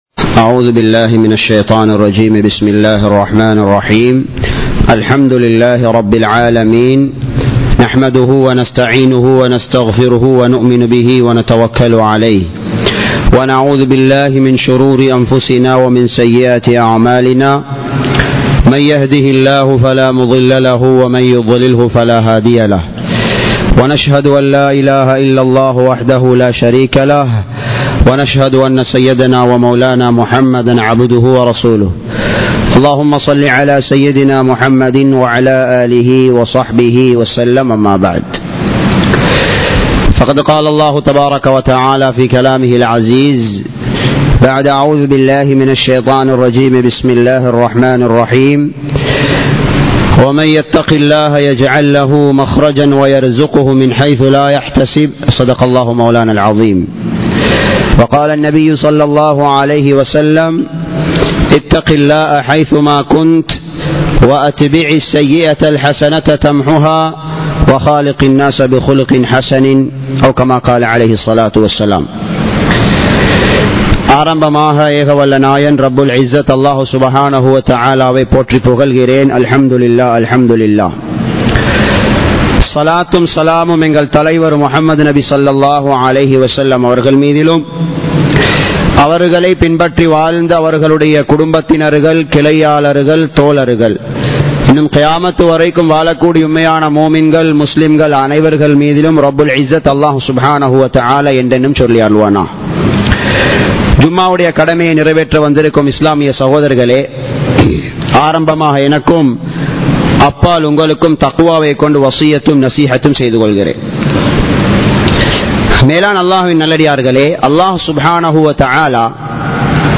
மஸ்ஜிதுக்கு நேரம் கொடுப்போம் | Audio Bayans | All Ceylon Muslim Youth Community | Addalaichenai
Dehiwela, Muhideen (Markaz) Jumua Masjith 2022-11-04 Tamil Download